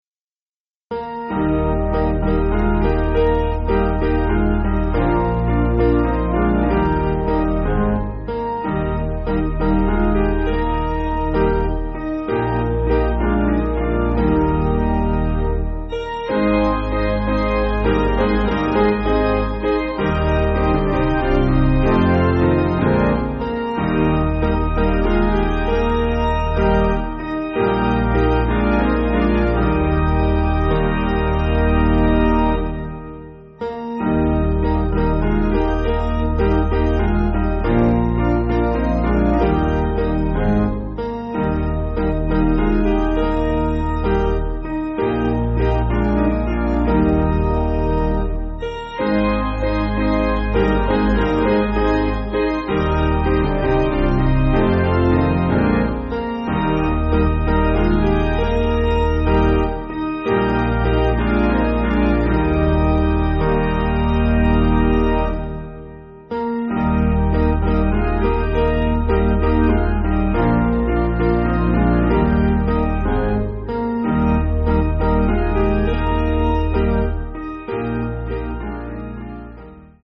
Basic Piano & Organ
(CM)   4/Eb